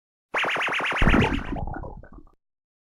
SFX_Box_Monster.mp3